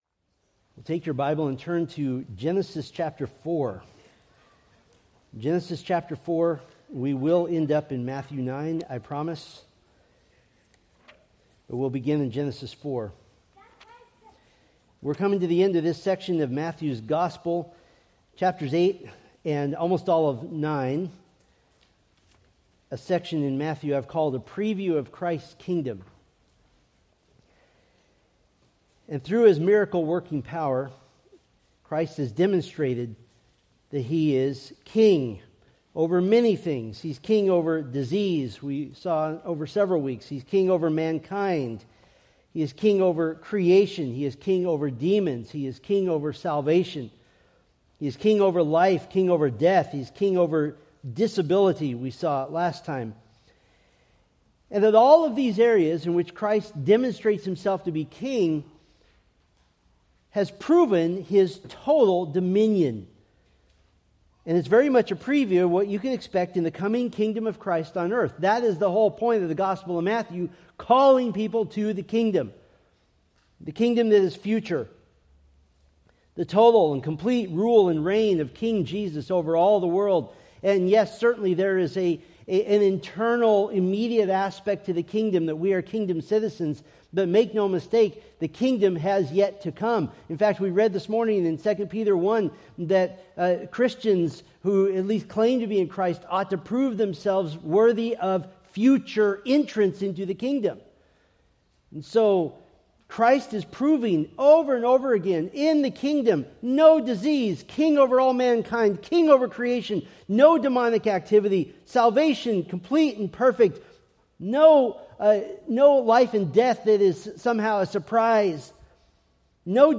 Preached January 19, 2025 from Matthew 9:33-35